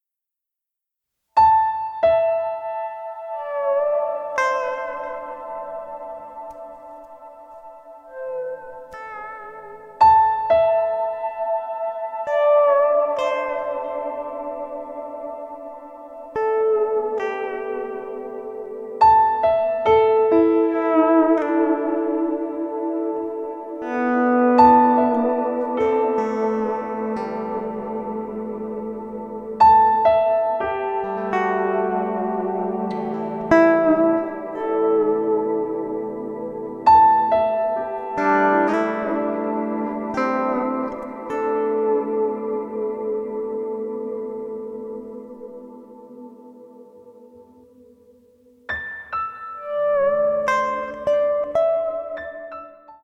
Electric guitar, Electric Baritone guitar